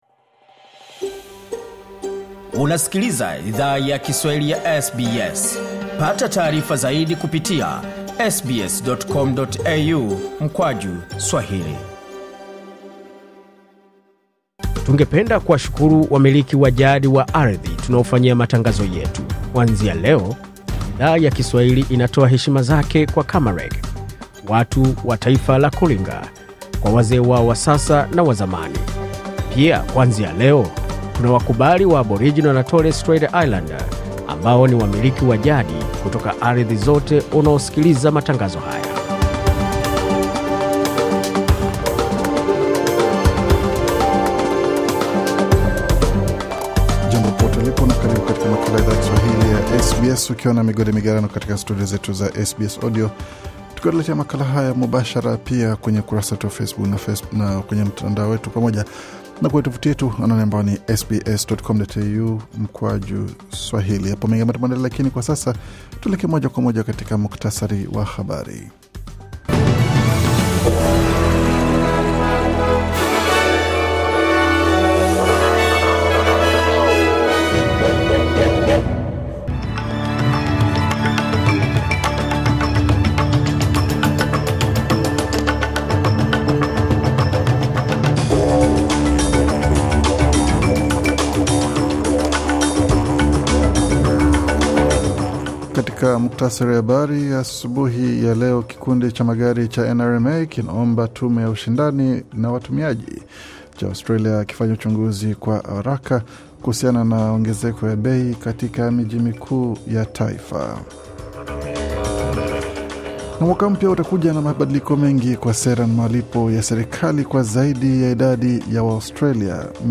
Taarifa ya Habari 31 Disemba 2024